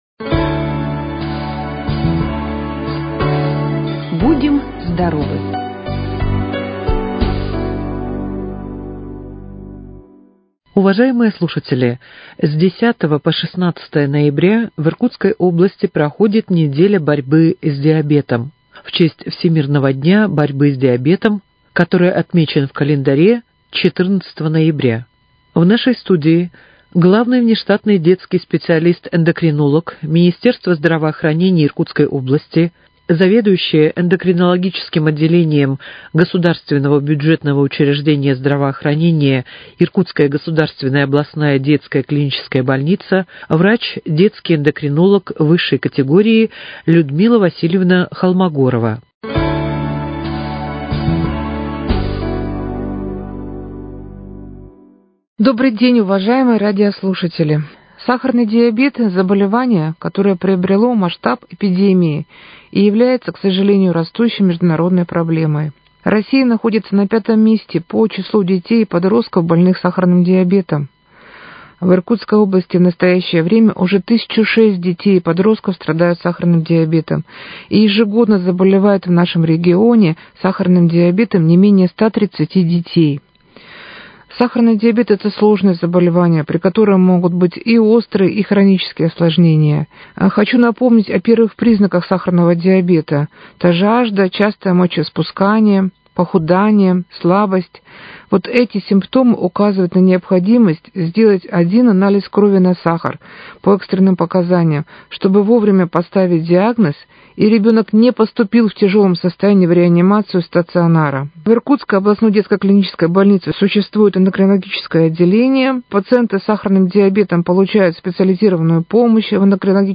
Предлагаем вашему очередную передачу из серии, которая готовится совместно с областным государственным бюджетным учреждением здравоохранения «Иркутский областной центр общественного здоровья и медицинской профилактики имени Углова Ф.Г.». С 10 по 16 ноября в Иркутской области проходит "Неделя борьбы с диабетом (в честь Всемирного дня борьбы с диабетом 14 ноября)".